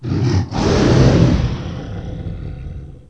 drag2.wav